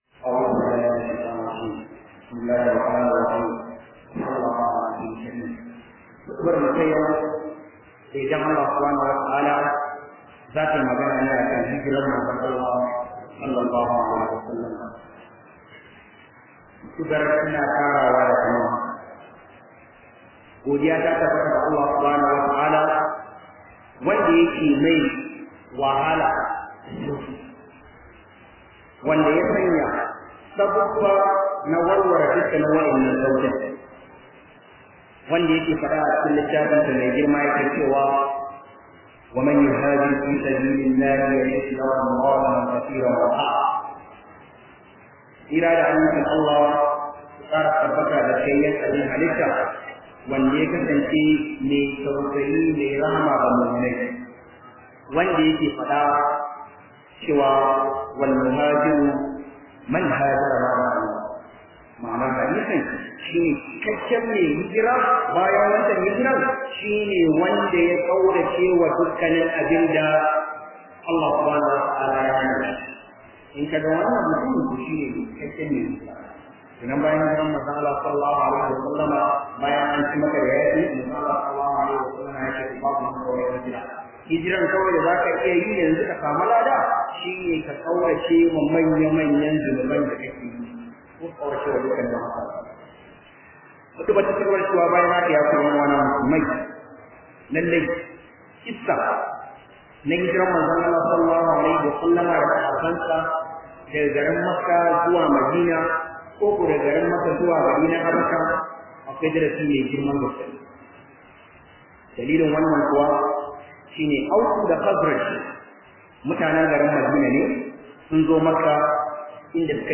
022 Khudubah kan Hijraah.mp3